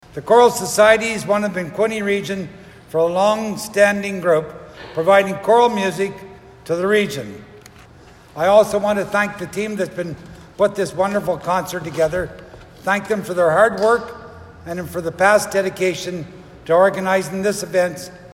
St Andrew’s Presbyterian Church in Belleville hosted the 60th anniversary concert of BCS.
Belleville City Councillor Garnet Thompson brought greetings from the city.